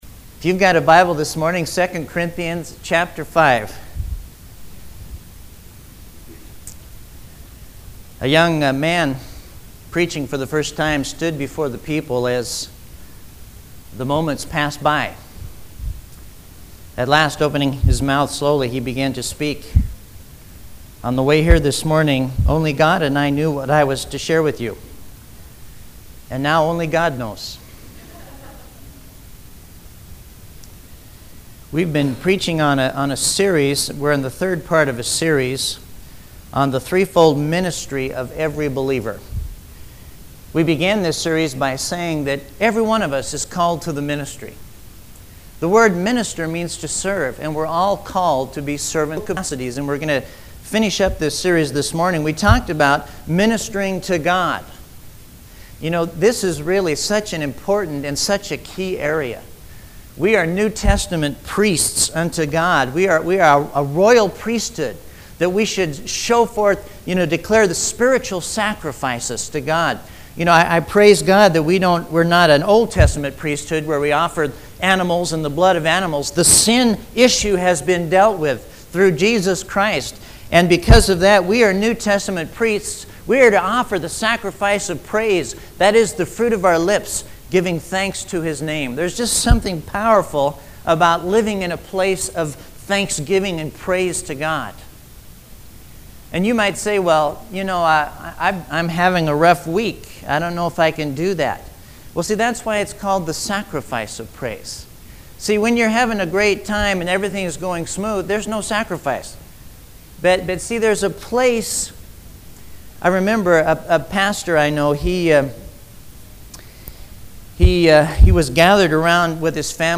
You must have Windows Media Player or a program that can listen to MP3 files to listen to these sermons.